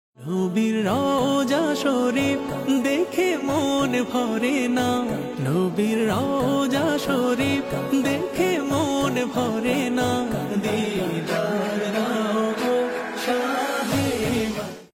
নাশীদটি